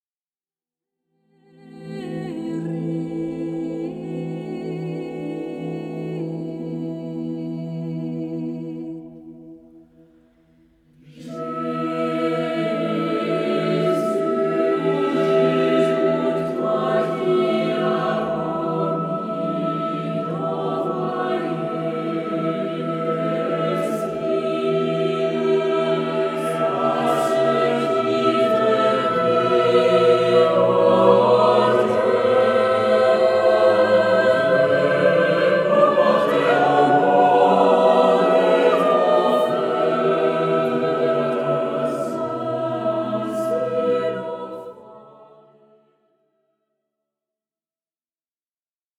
Harmonisations originales de chants d'assemblée